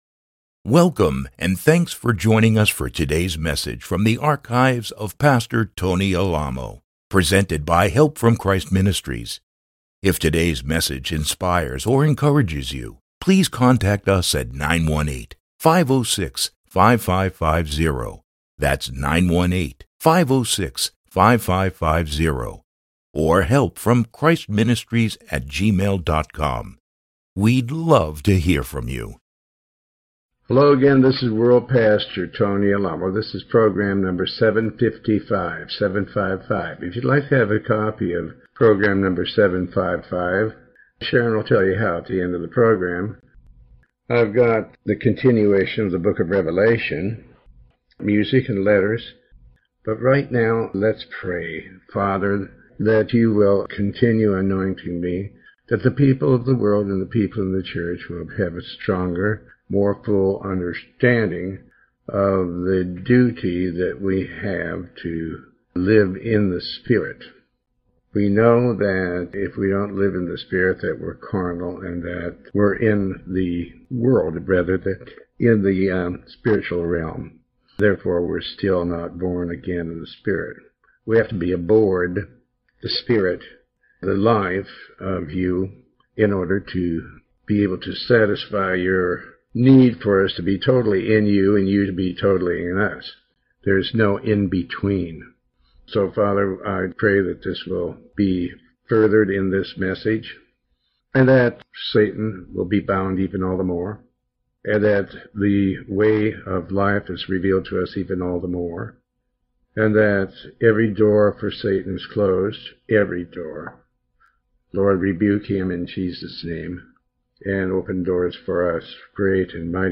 Sermon 755B